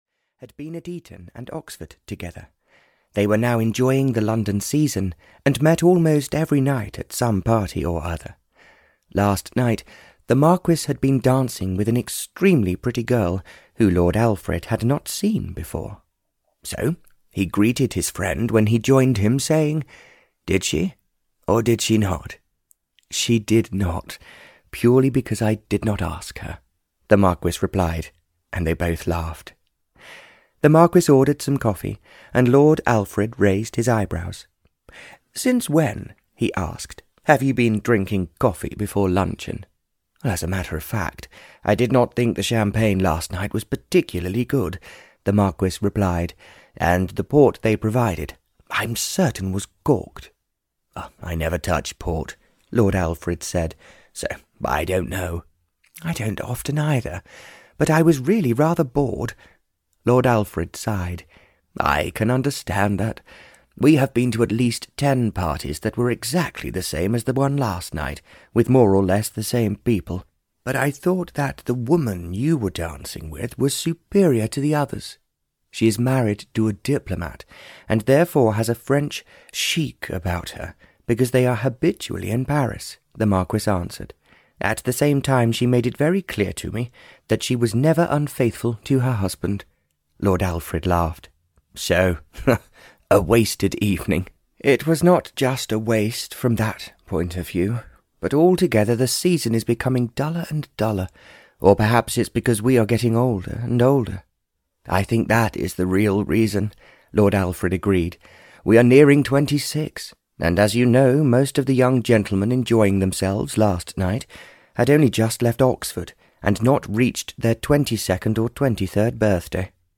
A Road to Romance (EN) audiokniha
Ukázka z knihy